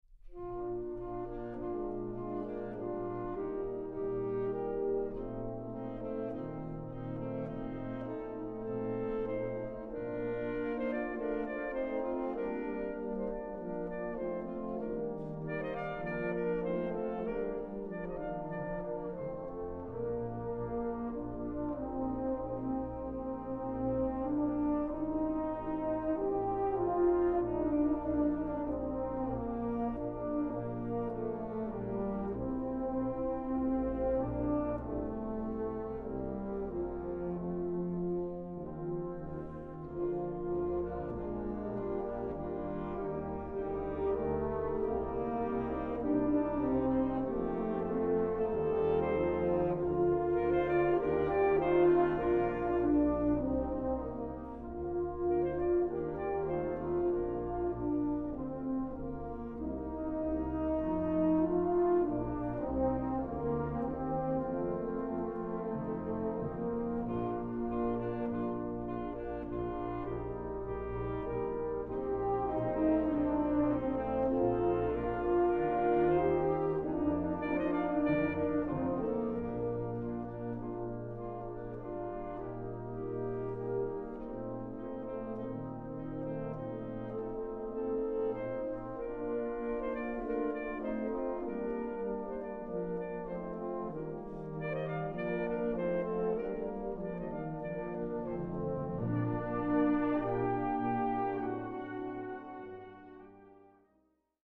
Fanfare